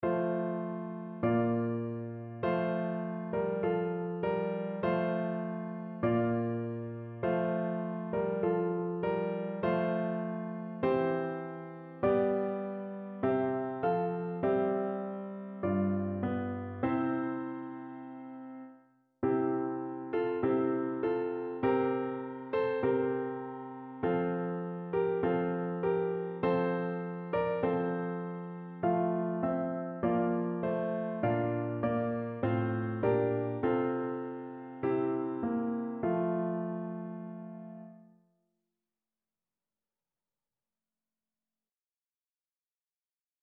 Notensatz 2 (4 Stimmen gemischt)